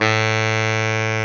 SAX B.SAX 13.wav